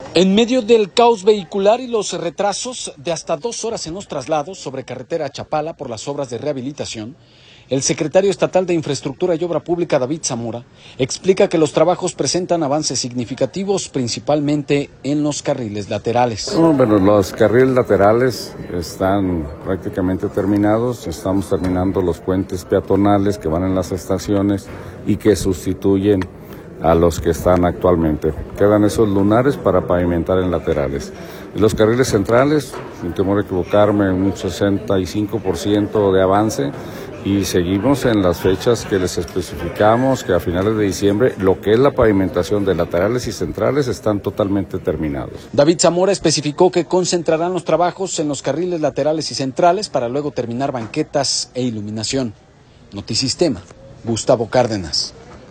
audio En medio del caos vehicular y los retrasos de hasta dos horas en los trasladados sobre carretera a Chapala por las obras de rehabilitación, el secretario estatal de Infraestructura y Obra Pública, David Zamora, explica que los trabajos presentan avances significativos, principalmente en carriles laterales.